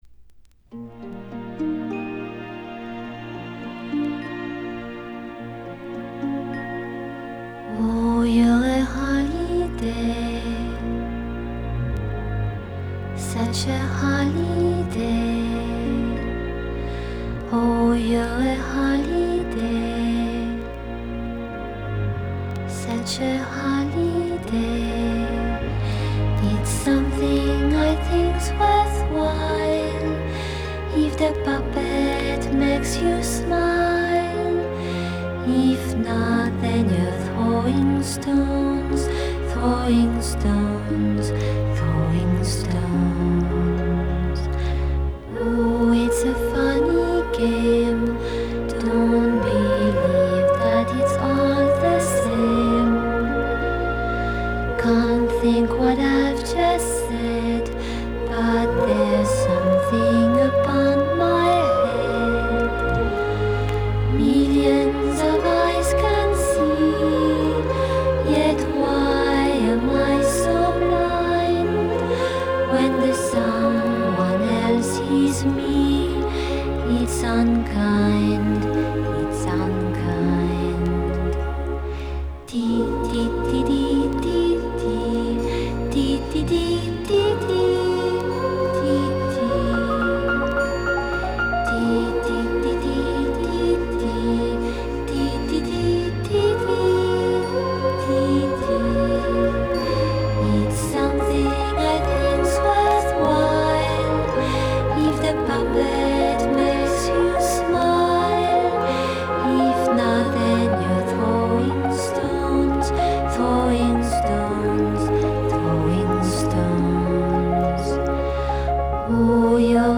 Genre: Pop, Vocal, Easy Listening